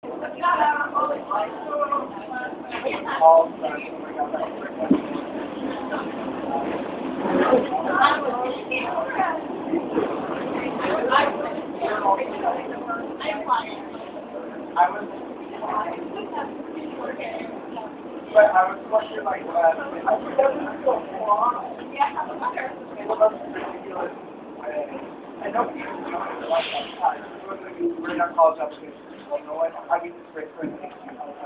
Field Recording #6
Location: R Train from Brooklyn into Manhattan
Sounds heard: People talking loudly, train rumbling
RTrainMarch5.mp3